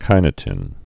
(kīnə-tĭn)